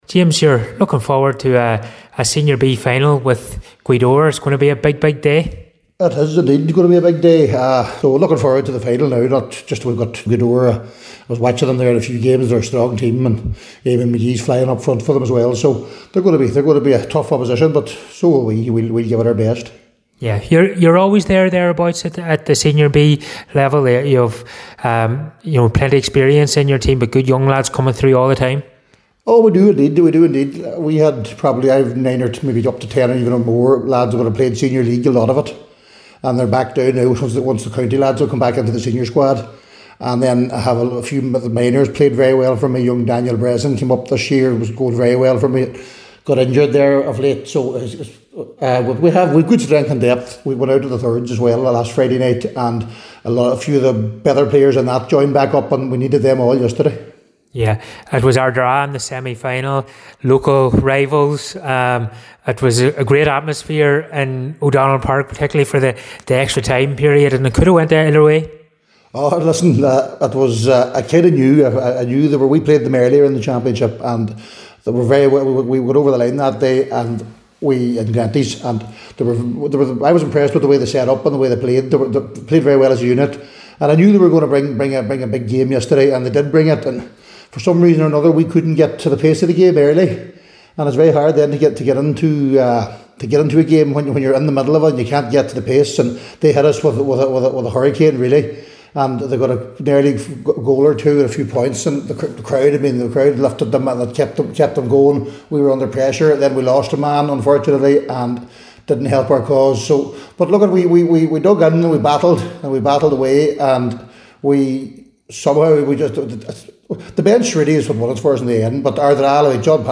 was at the press event for Highland Radio Sport and caught up with both camps.